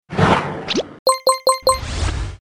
礼物UI弹窗4.MP3